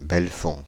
French pronunciation of « Belfonds »
Fr-Belfonds.ogg